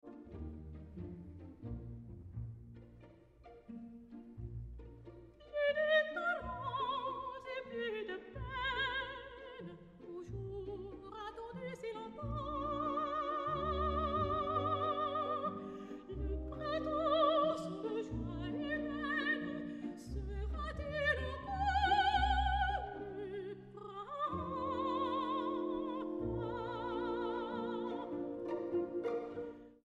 Sacred and Profane Arias